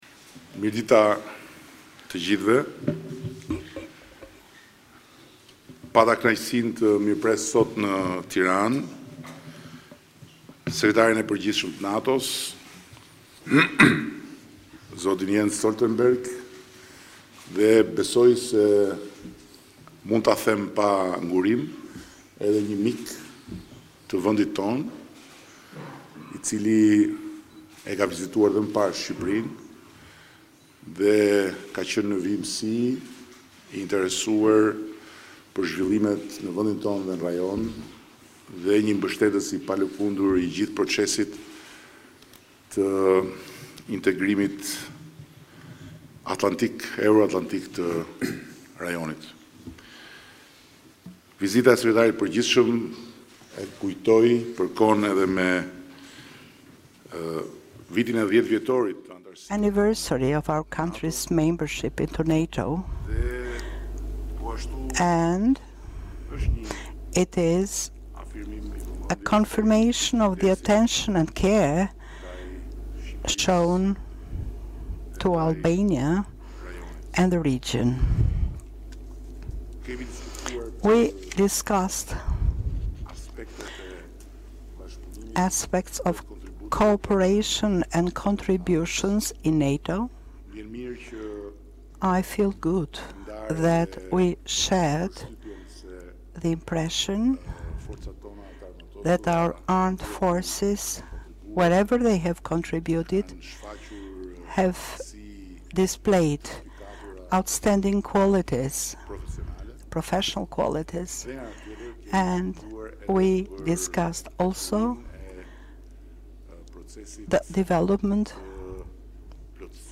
Joint press conference with NATO Secretary General Jens Stoltenberg and the Prime Minister of Albania, Edi Rama